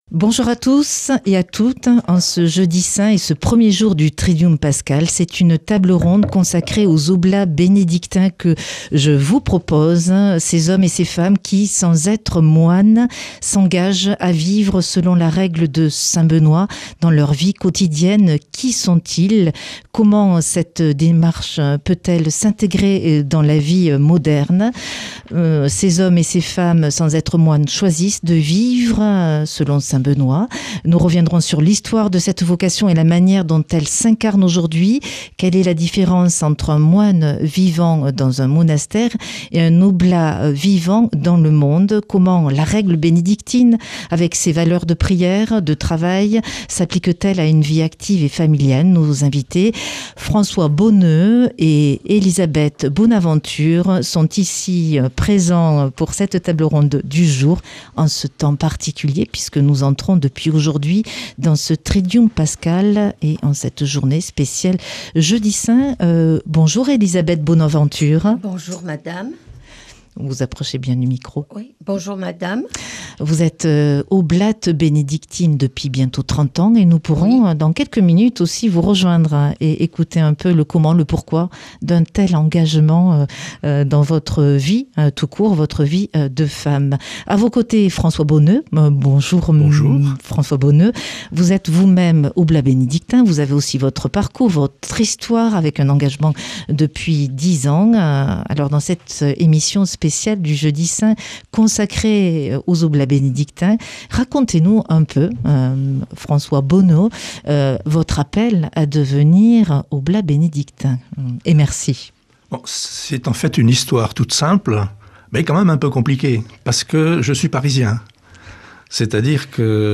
tableronde